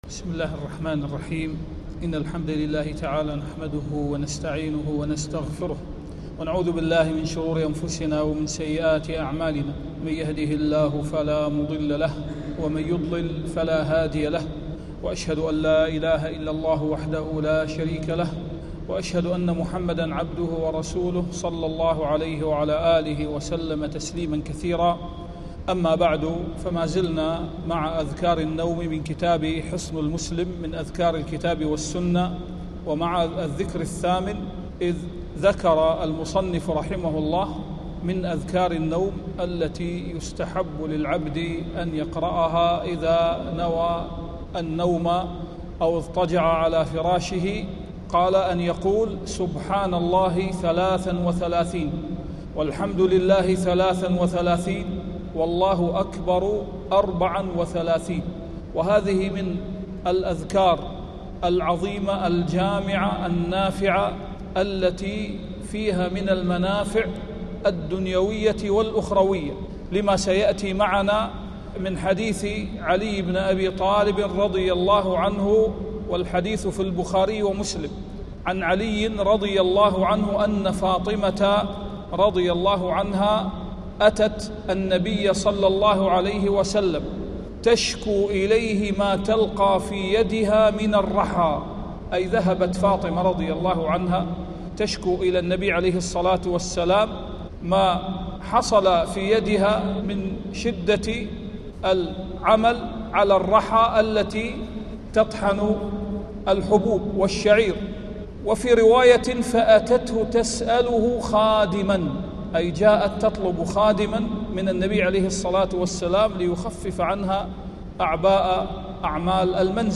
سلسلة دروس في معرفة معاني الأذكار والفوائد العملية والإيمانية منها ومناسباتها وفضلها.